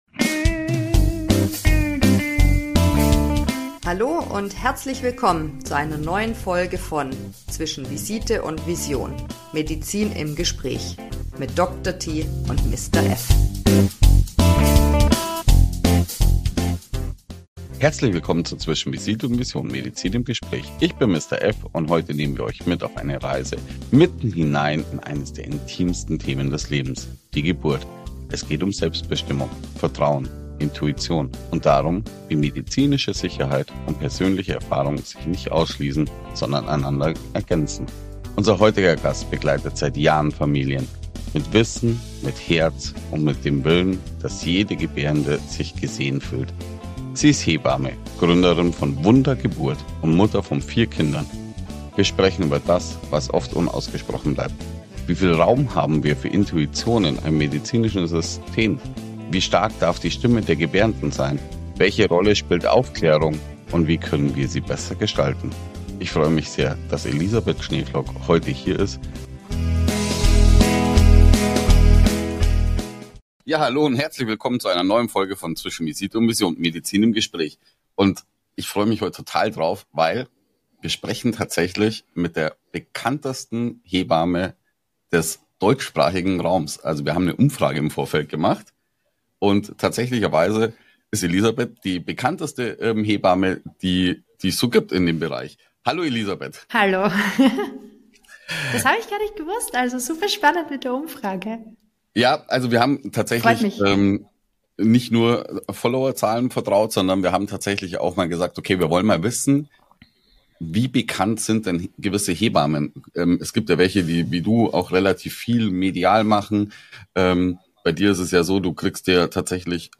Wir sprechen darüber, warum Aufklärung der Schlüssel zu Selbstvertrauen ist, wie Frauen ihre innere Stimme wiederfinden können und weshalb moderne Geburtshilfe beides braucht: evidenzbasierte Medizin und menschliche Begleitung. Ein ehrliches, fachlich fundiertes Gespräch über Geburt, Haltung, Verantwortung – und Vertrauen.